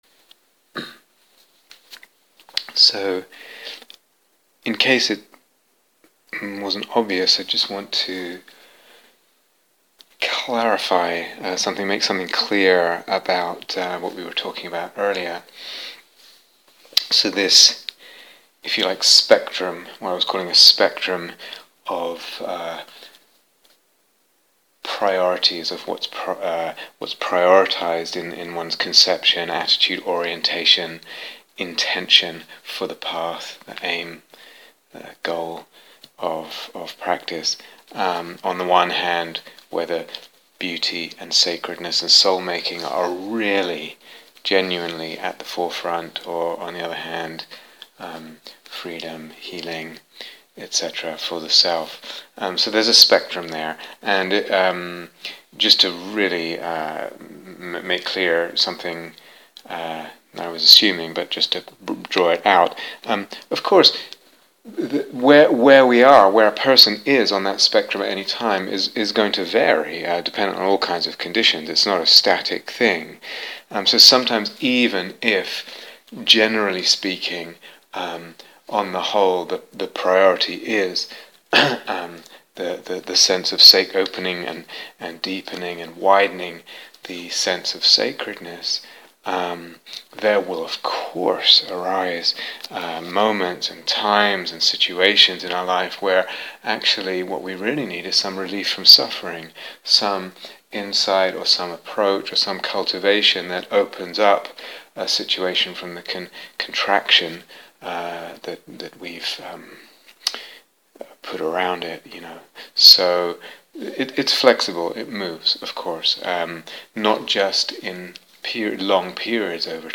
This series of talks is from a retreat